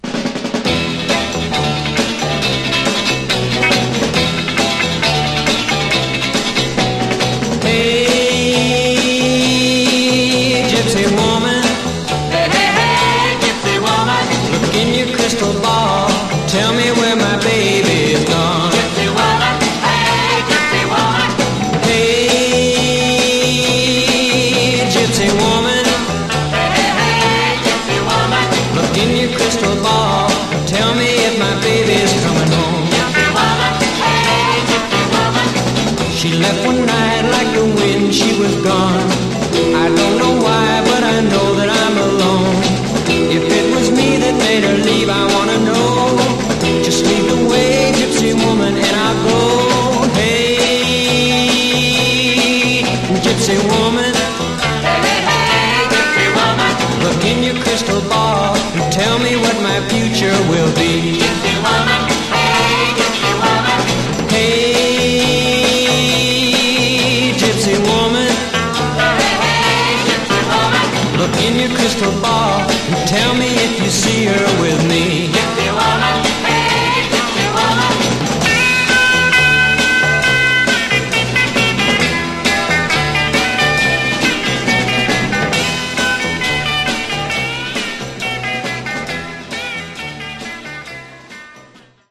Genre: Rock 'n' Roll